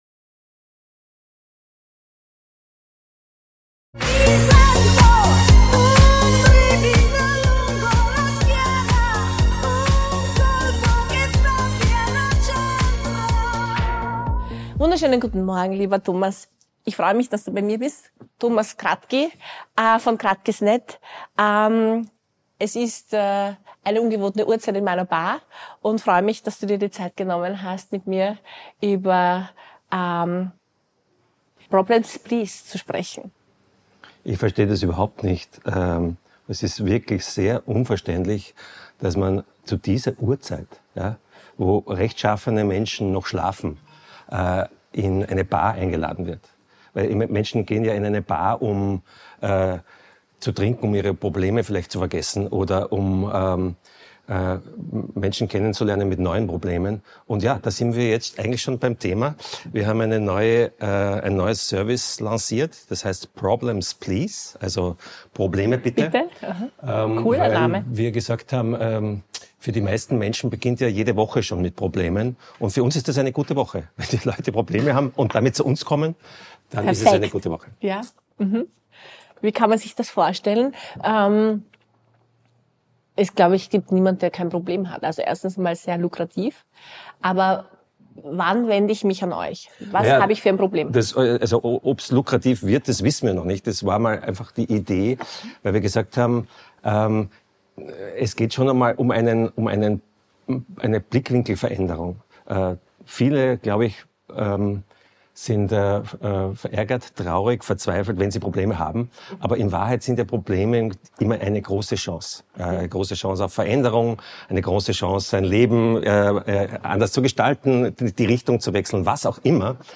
Zwischen Drink und Dialog entstehen Gespräche, die Tiefe haben - mal leise, mal laut, aber es immer wird Tacheles geredet.